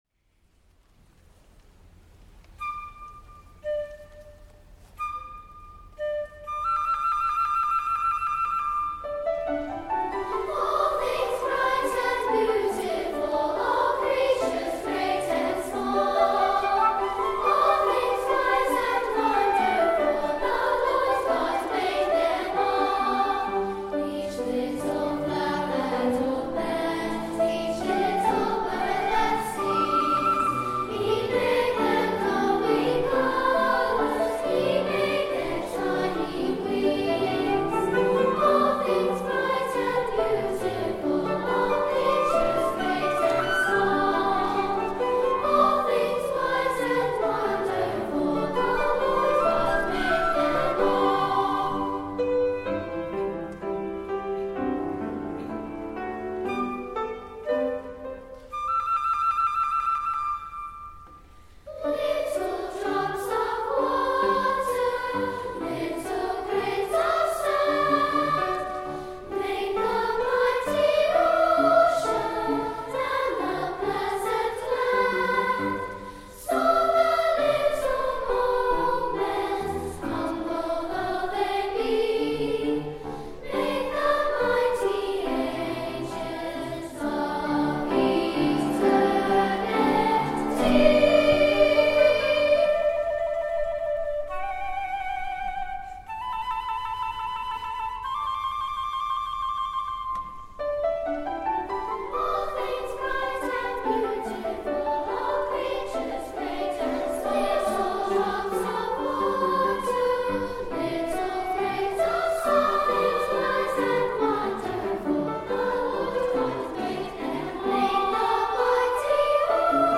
SS, Piano, and Flute